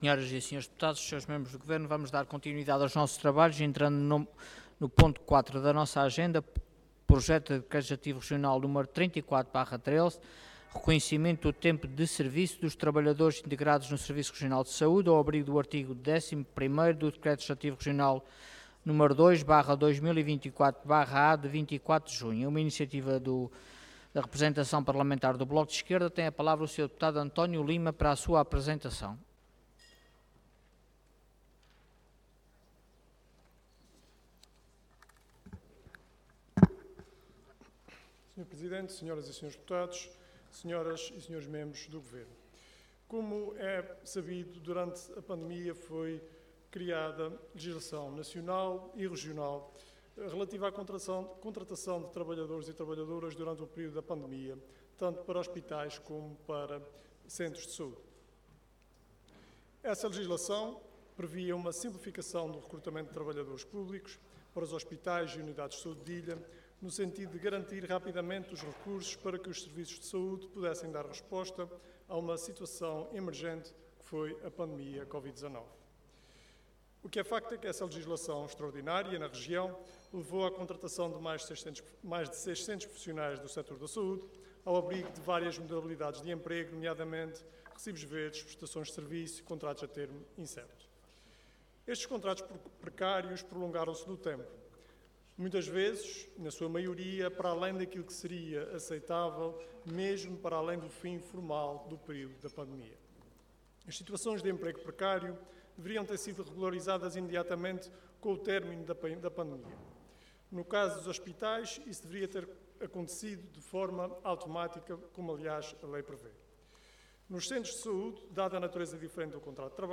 Detalhe de vídeo 9 de setembro de 2025 Download áudio Download vídeo Processo XIII Legislatura Reconhecimento do tempo de serviço dos trabalhadores integrados no SRS ao abrigo do artigo 11.º do Decreto Legislativo Regional n.º 2/2024/A, de 24 de junho Intervenção Projeto de Decreto Leg. Orador António Lima Cargo Deputado Entidade BE